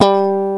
_GUITAR PICK 3.wav